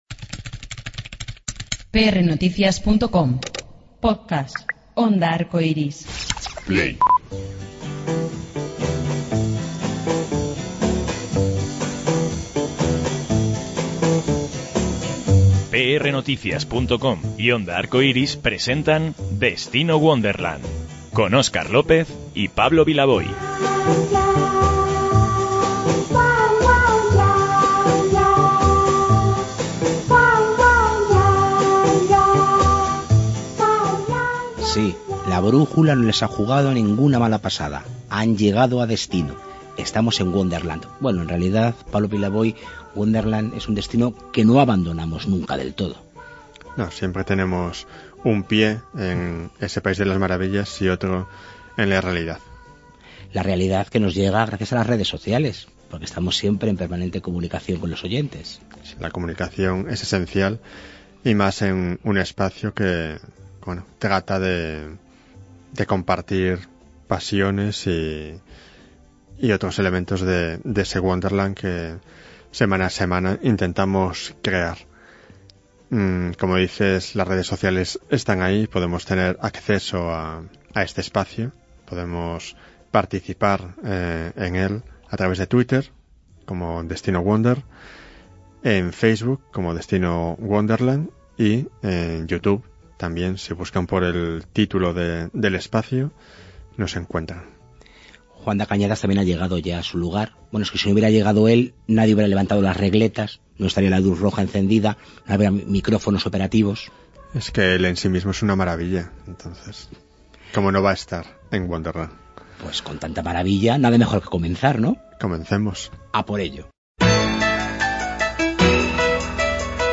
Viva Broadway narra la historia de Thomas Baker. Nosotros, desde Destino Wonderland, hemos conversado con sus protagonistas para que nos hablen de esta estupenda obra.
Llevamos los micrófonos de Destino: Wonderland hasta el patio de butacas, algunos de los intérpretes de Viva Broadway se sentaron en la corbata del escenario y revivimos la magia del musical.